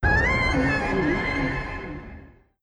File:Mothra Imago Roar 2024.wav
Mothra_Imago_Roar_2024.wav